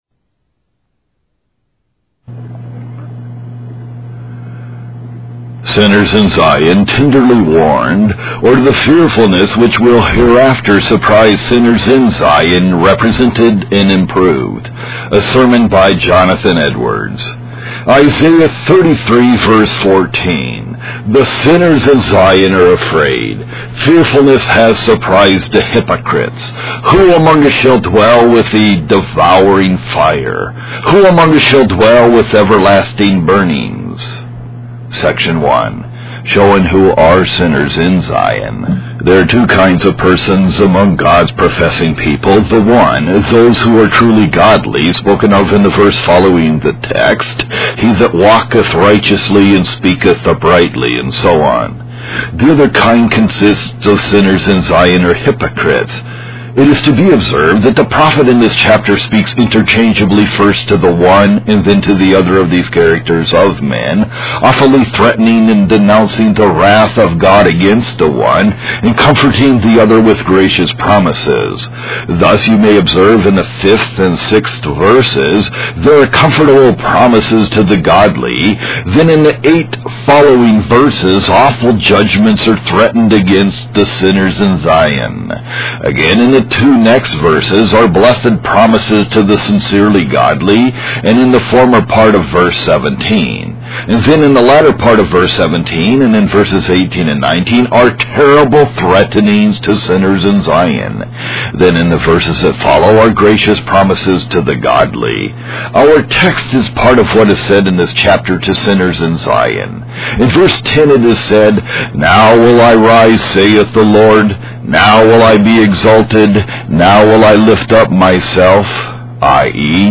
Wise Desire Ministries helps convey various Christian videos and audio sermons.